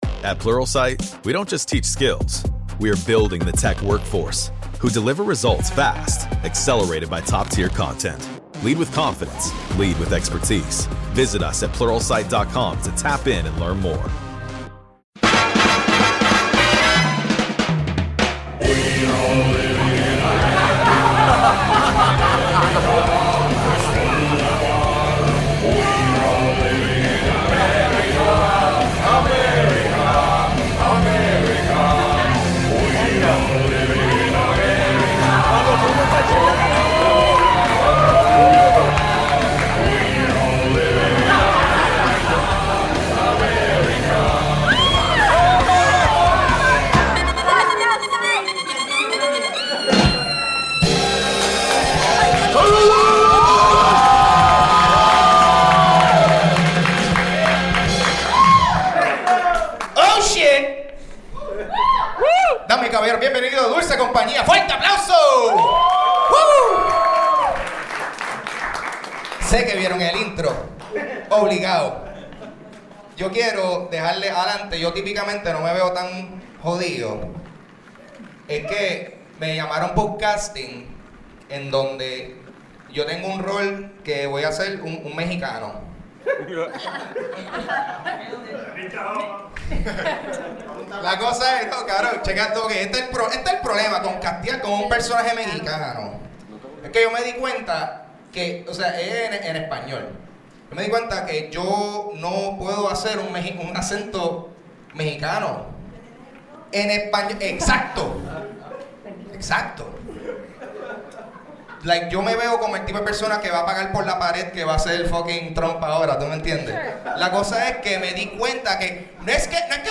Grabado el 28 de enero de 2017 en Celebrate.